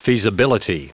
Prononciation du mot : feasibility
feasibility.wav